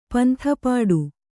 ♪ pantha pāḍu